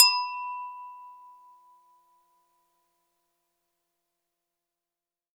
Glass1 B4.wav